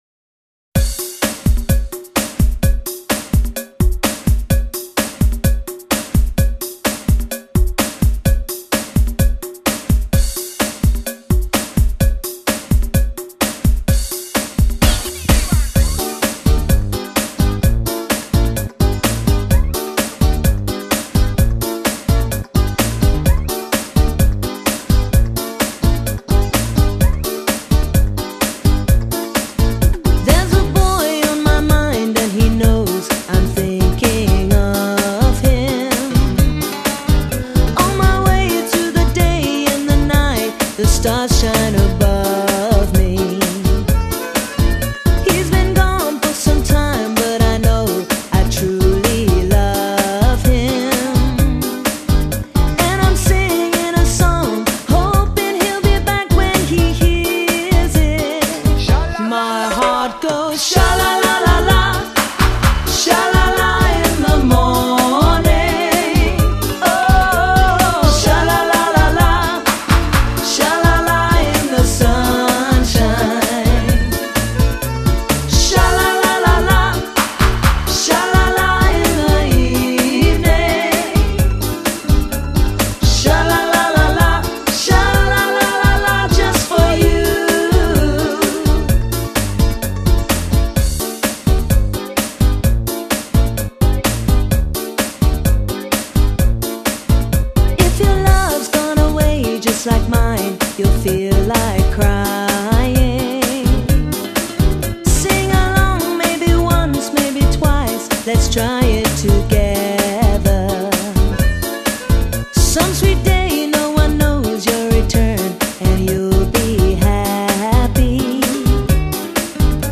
音乐风格: 电子